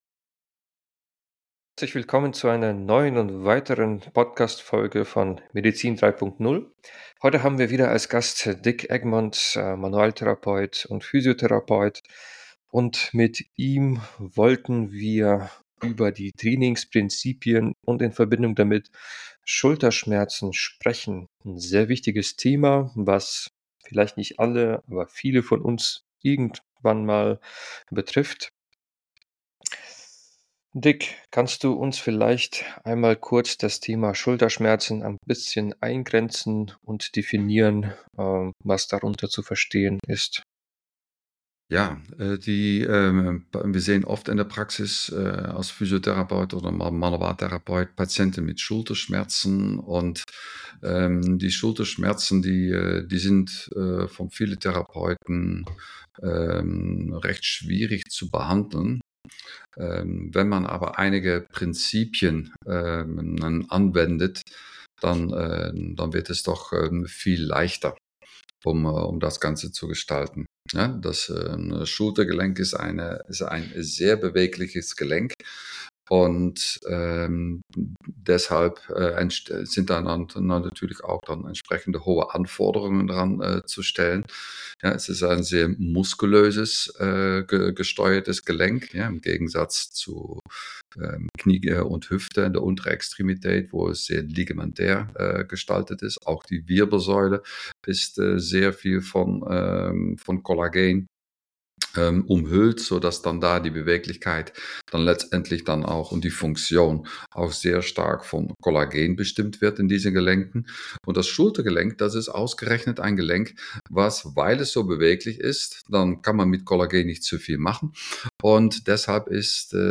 In diesem Gespräch geht es um Schulterbeschwerden, operative Maßnahmen und medikamentöse Behandlungen.